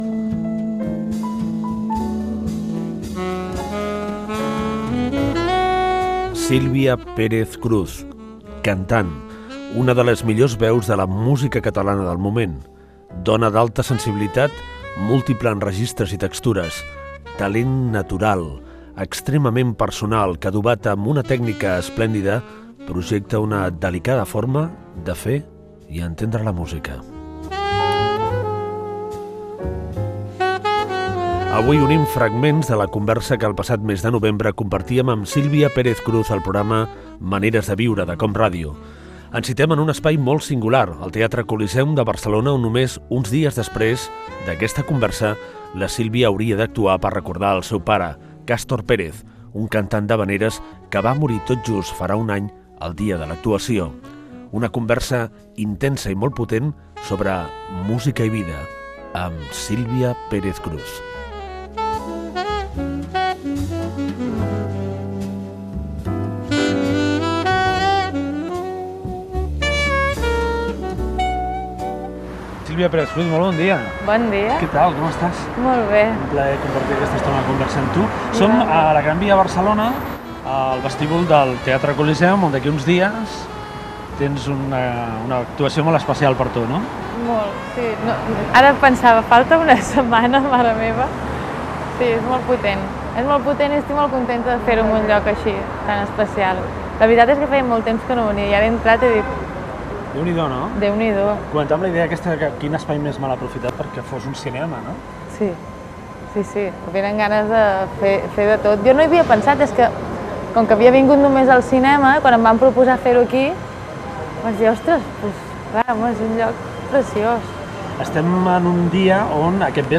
Presentació i fragment d'una conversa amb la cantant Sílvia Pérez Cruz, enregistrada el mes de novembre de l'any anterior
Entreteniment
Fragment extret de l'arxiu sonor de COM Ràdio.